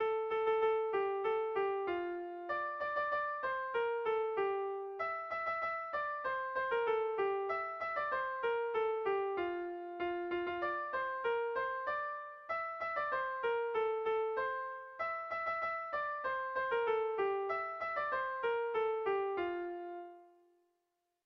Bertso melodies - View details   To know more about this section
Irrizkoa
Zortzikoa, berdinaren moldekoa, 6 puntuz (hg) / Sei puntukoa, berdinaren moldekoa (ip)
8A / 8A / 10 / 6A / 8A / 8A / 10 / 6A (hg) | 8A / 8A / 16A / 8A / 8A / 16A (ip)
ABDE..